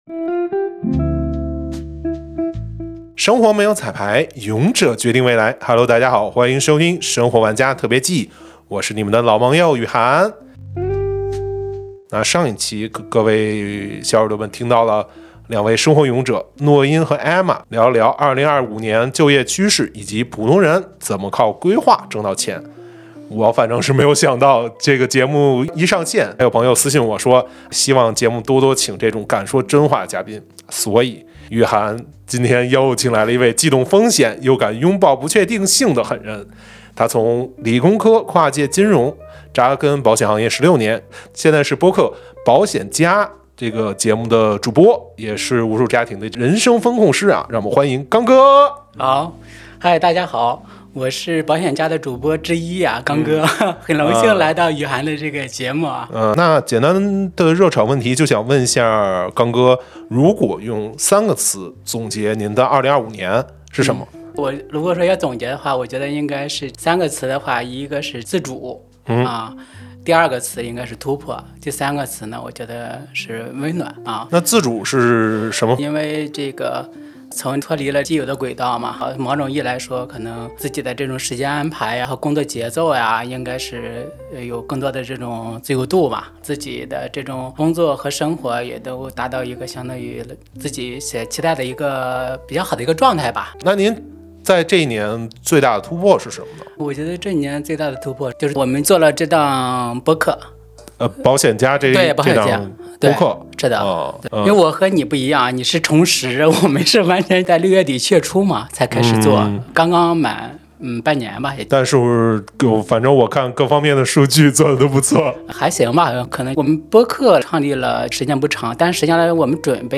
感谢「荔枝」提供录音棚场地支持；